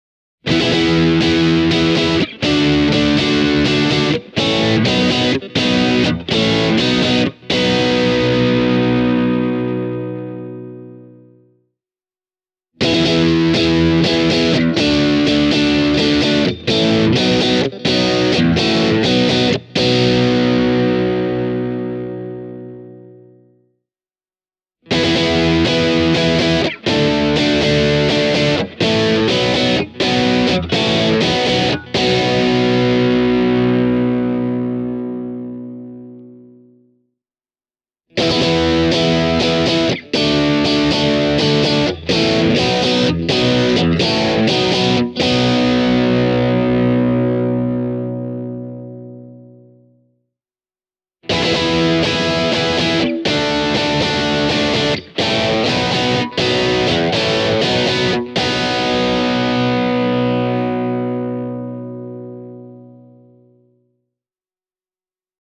Brittityylinen särö:
fender-am-pro-stratocaster-e28093-british-drive.mp3